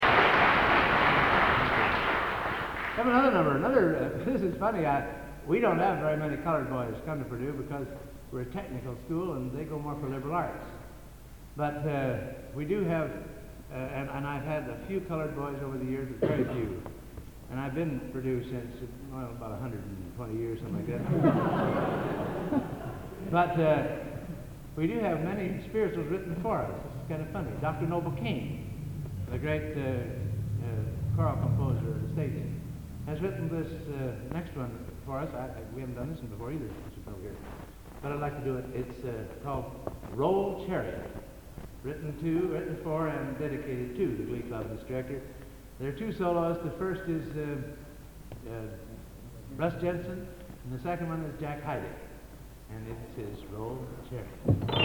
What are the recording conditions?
Location: Plymouth, England